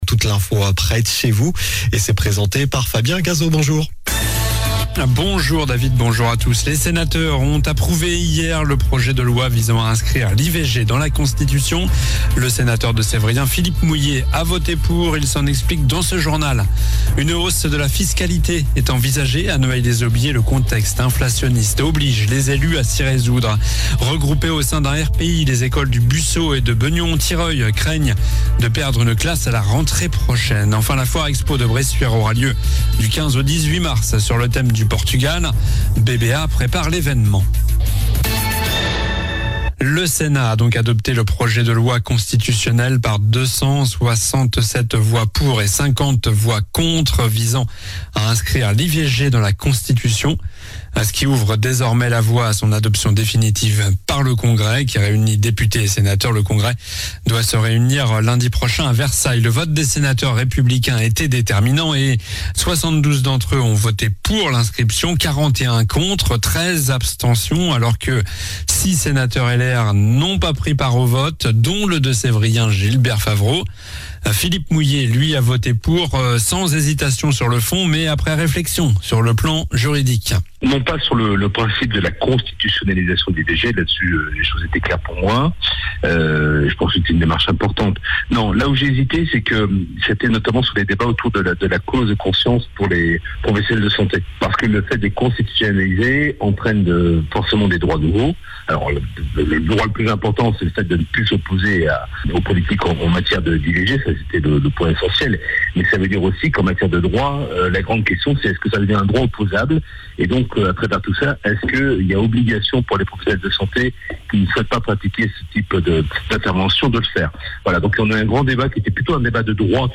Journal du jeudi 29 février (midi)